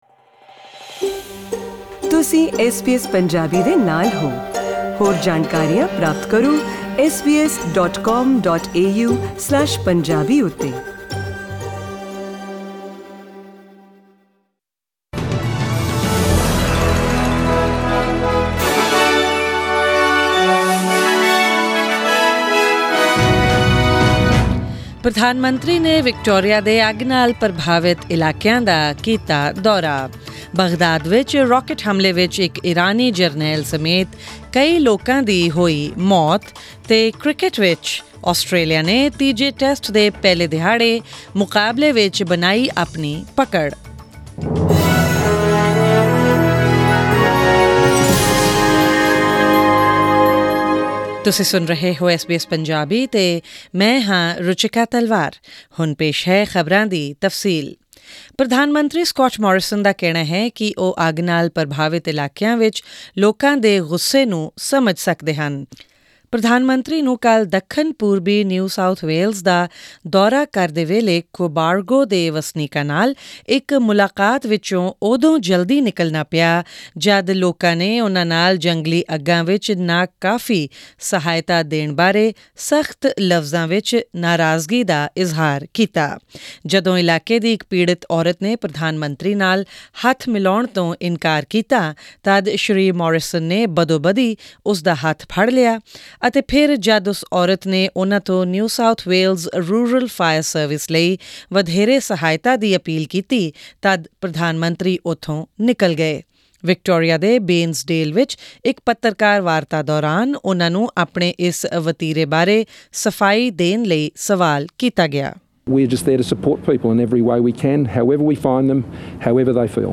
SBS Punjabi News: January 3, 2020